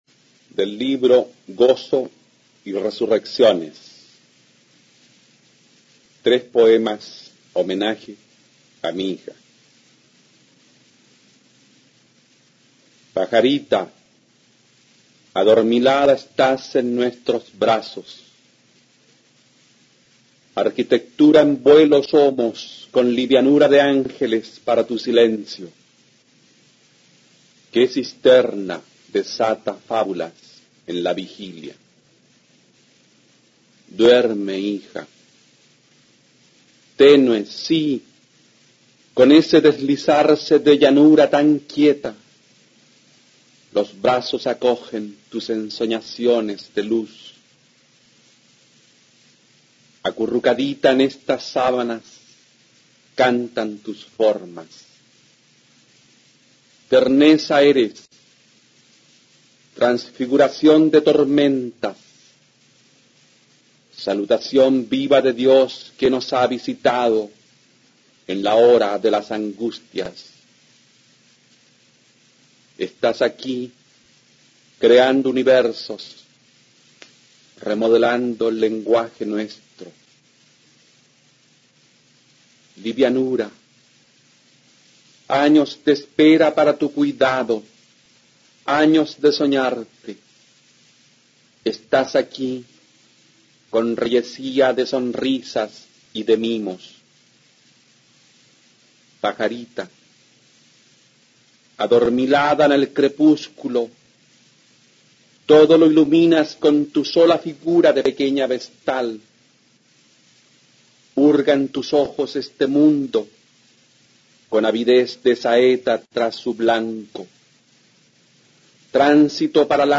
Aquí se puede oír al escritor chileno Luis Droguett Alfaro leyendo Homenaje a mi hija (tres poemas), extraído del libro "Gozo y resurrecciones".